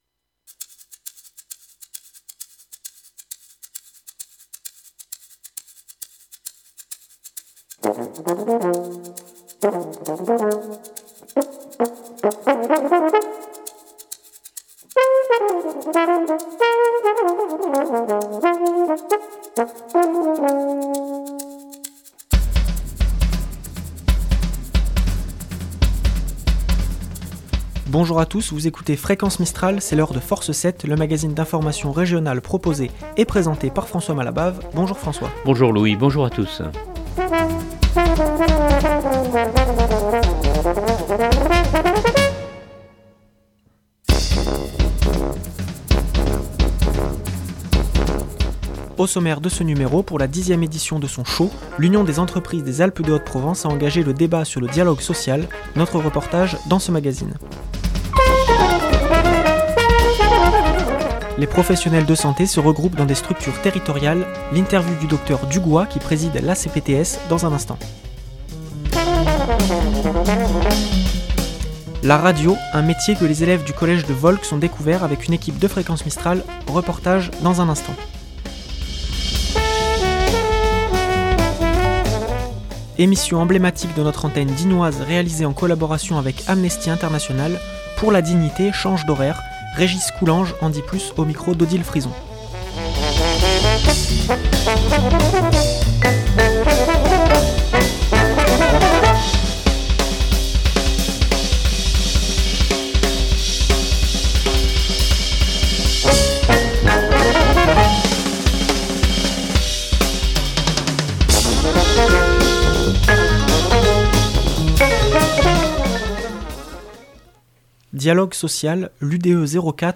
un magazine d’information régional